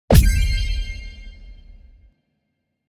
Magic-poof-sound-effect.mp3